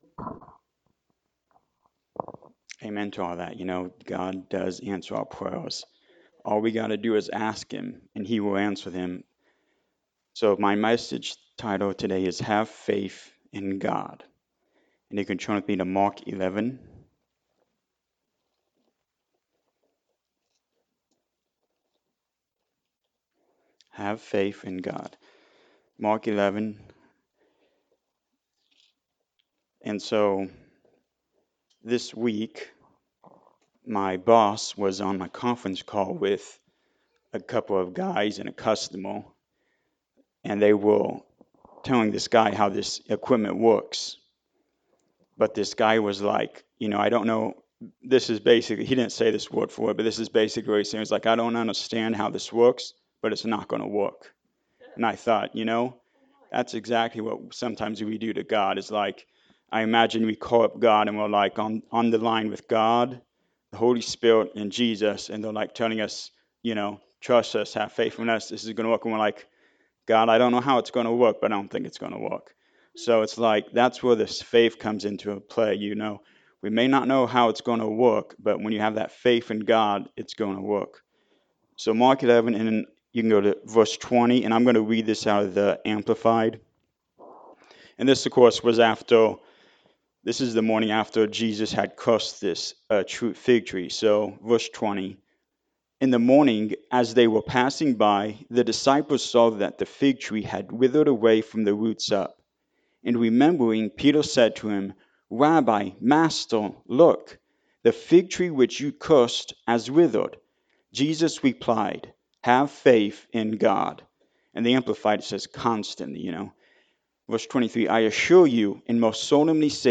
Mark 11:20-26 Service Type: Sunday Morning Service Have faith in God.
Sunday-Sermon-for-March-3-2024.mp3